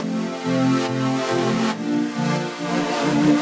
Index of /musicradar/french-house-chillout-samples/140bpm/Instruments
FHC_Pad C_140-C.wav